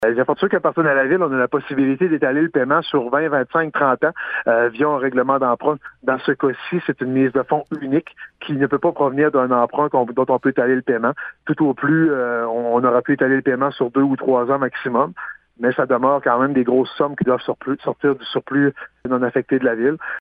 La maire de Gaspé, Daniel Côté, explique qu’il était impossible d’investir un tel montant dans un édifice qui ne lui appartient pas :